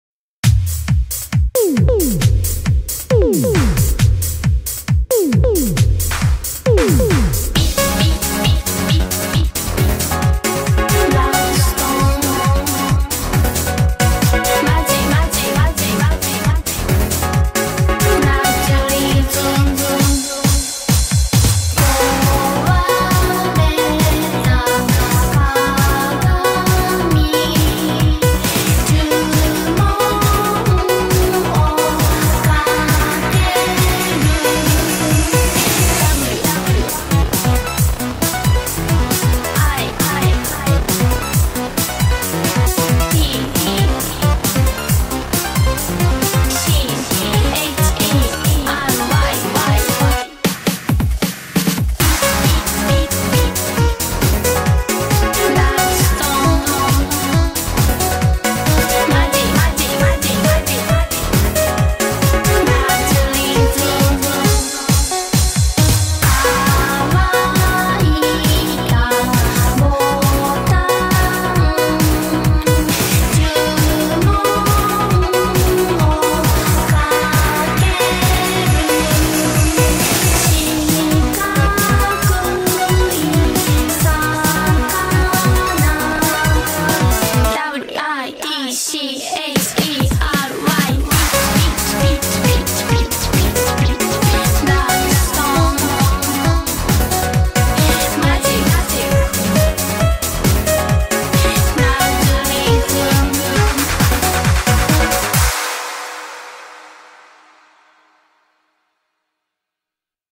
BPM135
The original song is 153 BPM; this remix is 135.